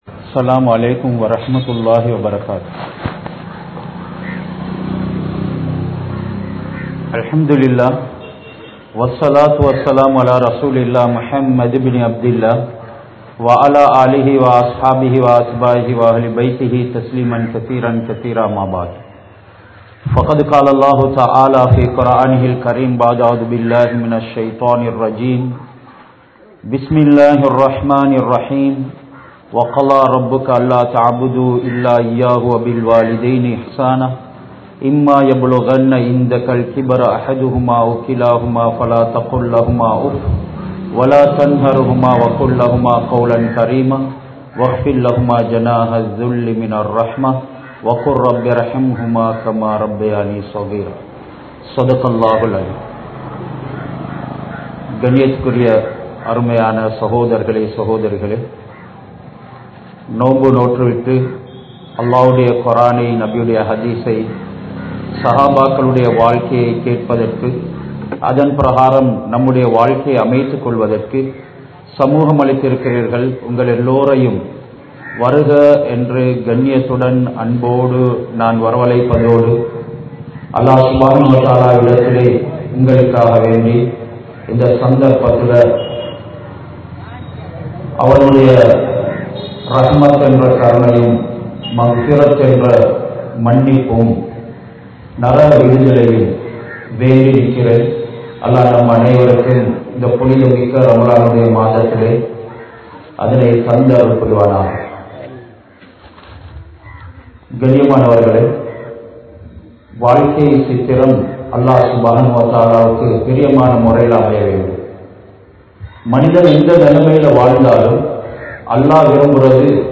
Pillaihal Petroarhalukku Seiya Veandiyavaihal (பிள்ளைகள் பெற்றோர்களுக்கு செய்ய வேண்டியவைகள்) | Audio Bayans | All Ceylon Muslim Youth Community | Addalaichenai